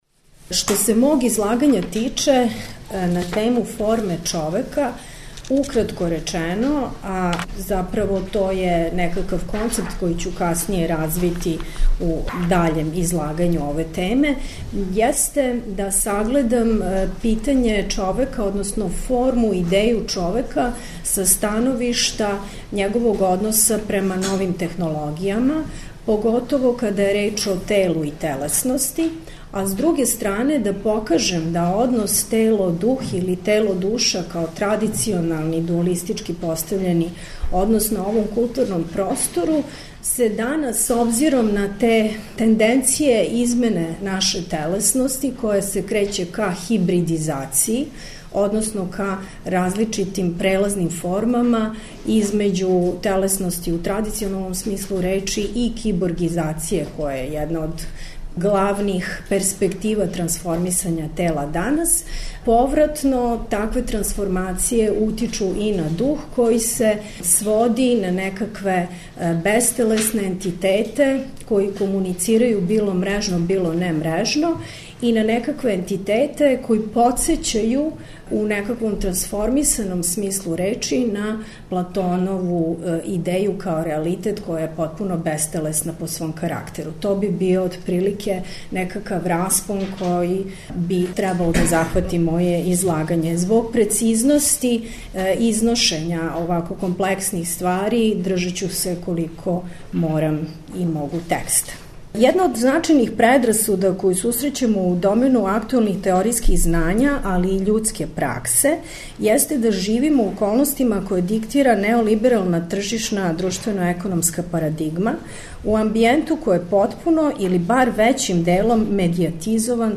У циклусу ПРОБЛЕМ ФОРМЕ четвртком и петком емитујемо снимке са истоименог научног скупа који је крајем прошле године организовало Естетичко друштво Србије.
Научни скупови